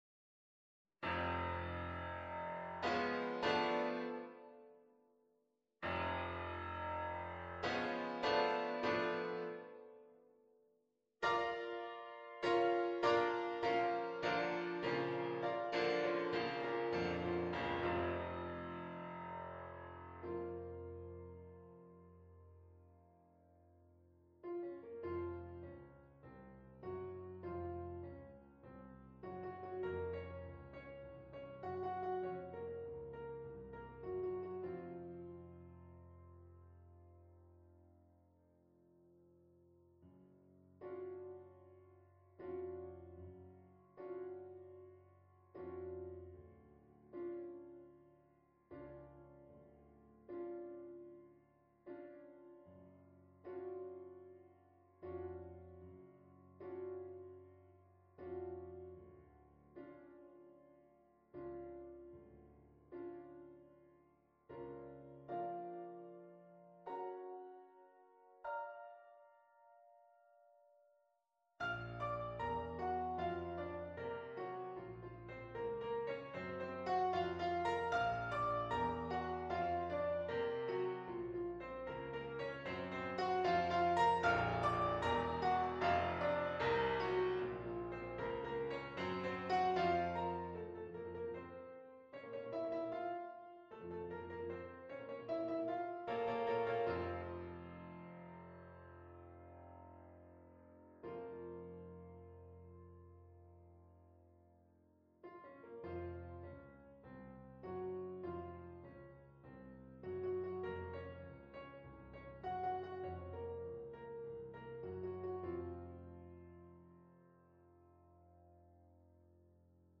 fore-cattle-noteperformer-piano.mp3